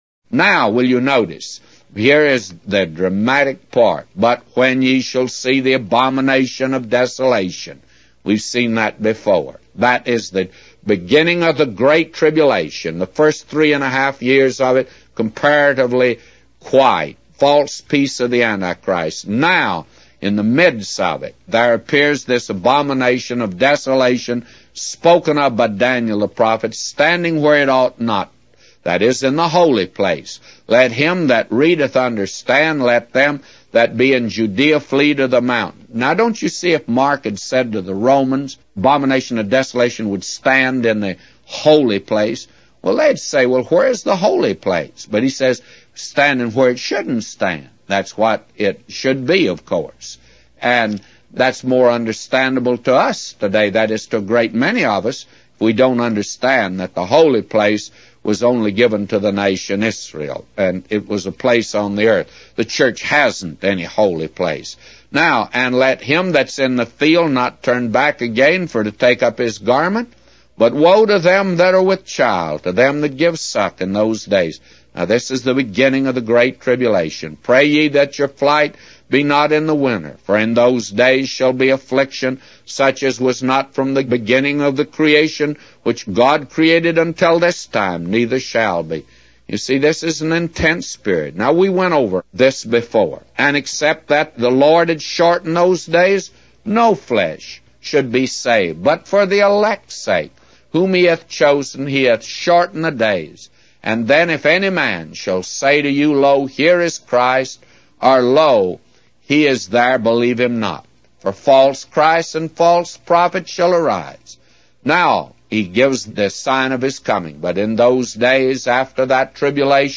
Daily Bible Reading